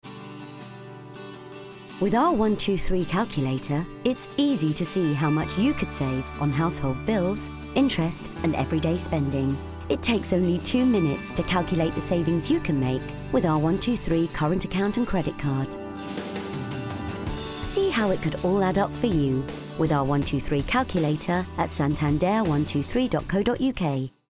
Straight
Commercial, Smooth, Warm, Reassuring